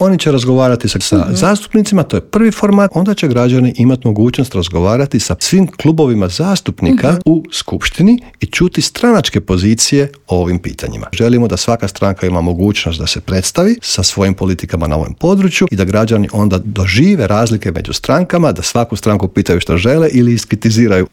U Intervjuu Media servisa gostovao je predsjednik zagrebačke Gradske skupštine, Joško Klisović, koji je istaknuo važnost konferencije, njezine ciljeve i objasnio koliko je bitna uloga građana.